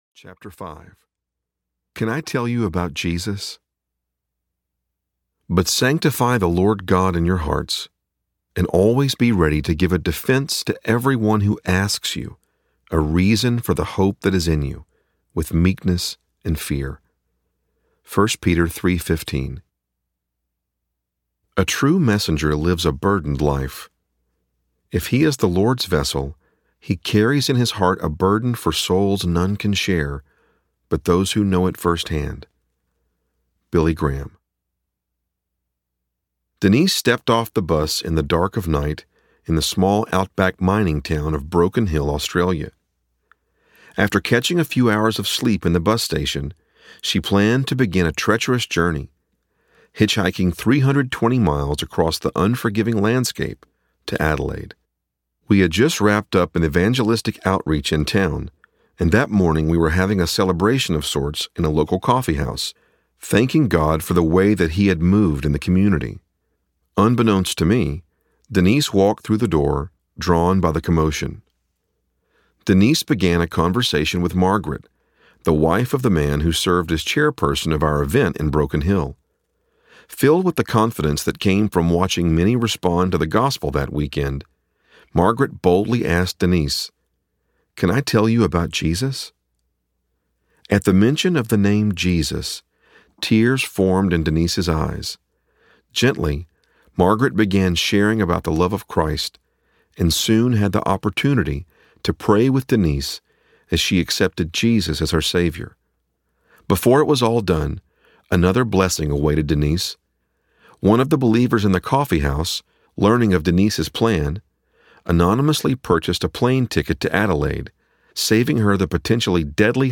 Redeemed Audiobook
Narrator